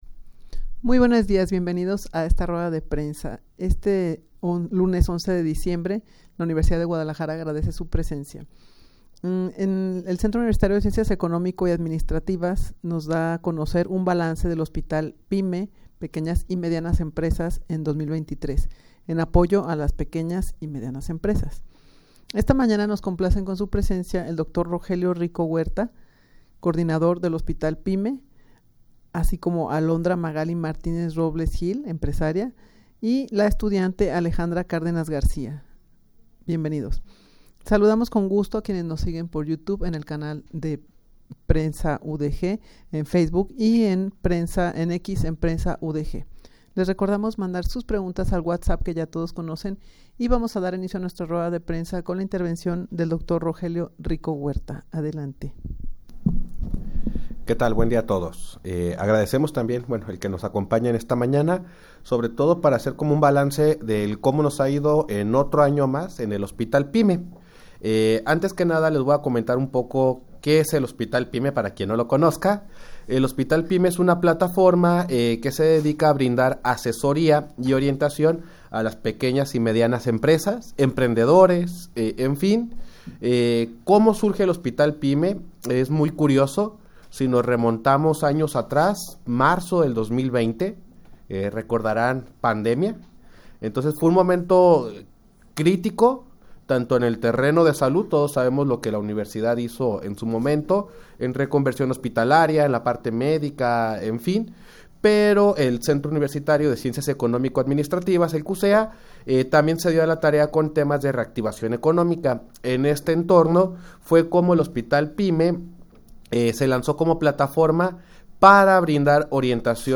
Audio de la Rueda de Prensa
rueda-de-prensa-para-dar-a-conocer-un-balance-del-hospital-pyme-en-2023.mp3